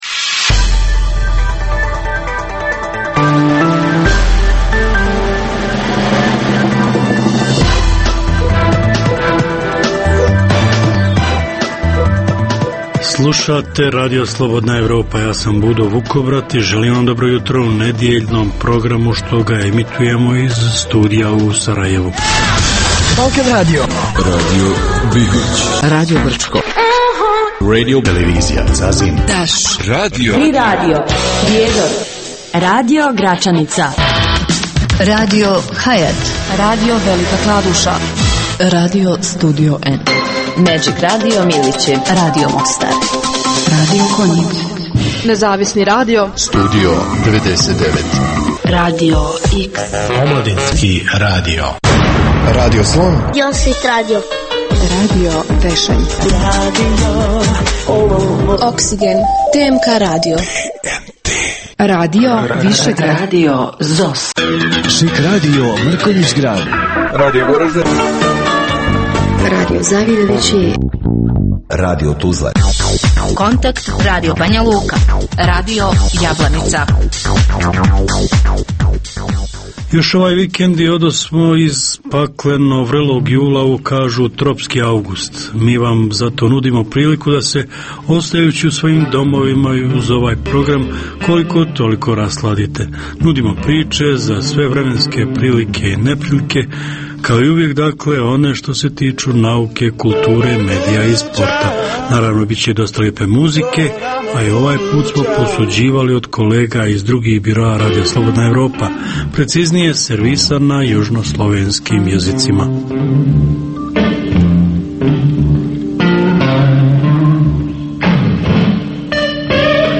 Jutarnji program namijenjen slušaocima u Bosni i Hercegovini. Sadrži novosti iz svijeta nauke, medicine, visokih tehnologija, sporta, filma i muzike.